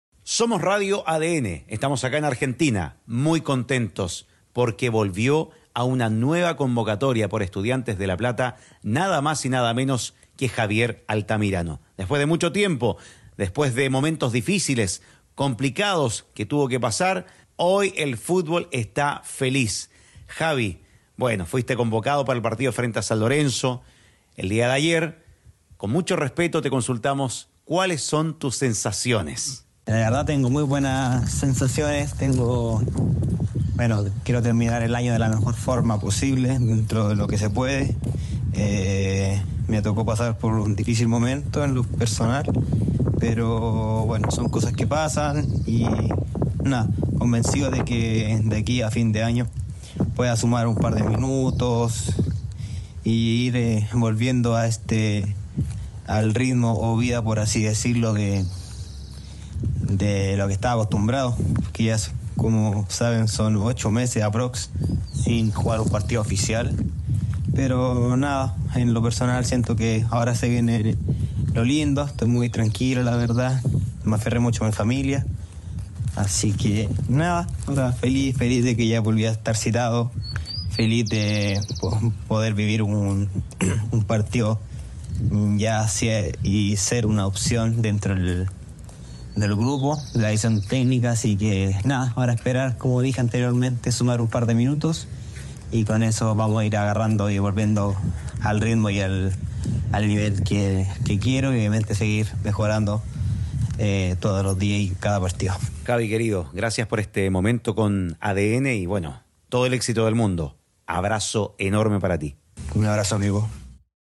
El ariete de Estudiantes de La Plata conversó con ADN Deportes y reveló sus sensaciones tras estar presente ante San Lorenzo.